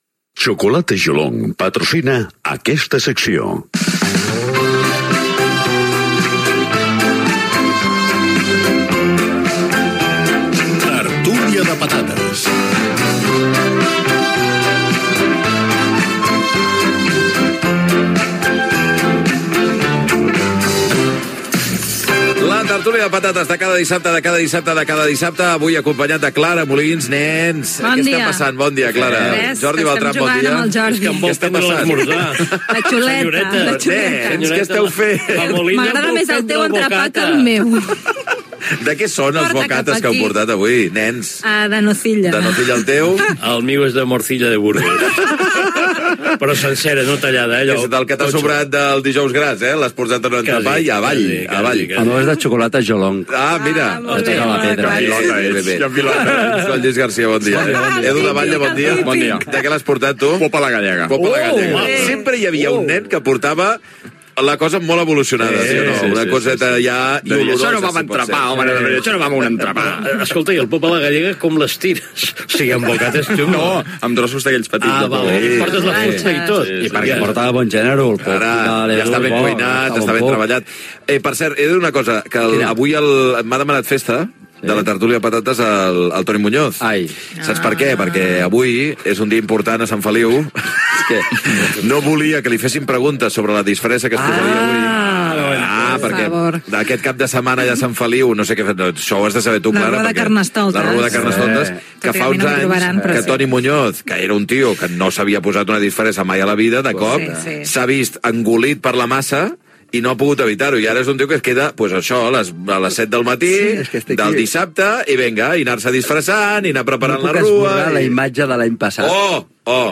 bbd4ebc55e38330ab6abd892dde0847cb2991f23.mp3 Títol RAC 1 Emissora RAC 1 Barcelona Cadena RAC Titularitat Privada nacional Nom programa Via lliure Descripció Secció "Tertúlia de patates".
Gènere radiofònic Entreteniment